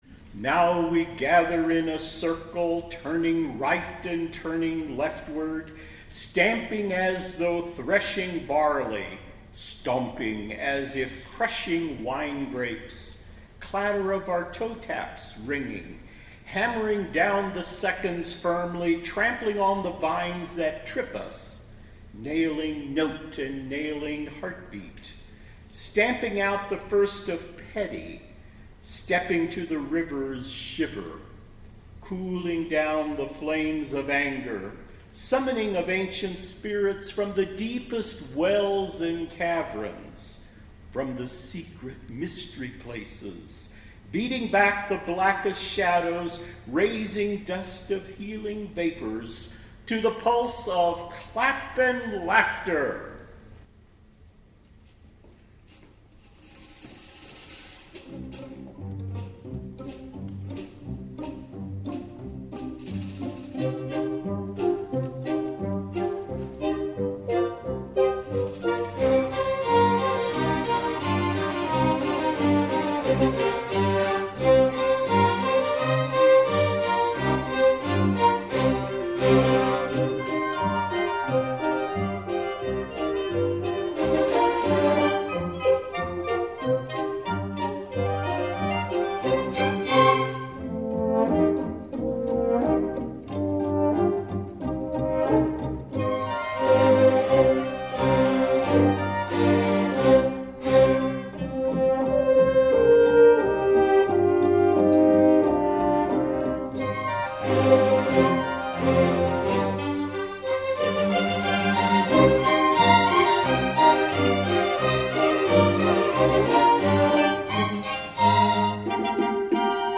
a four-movement suite for chamber orchestra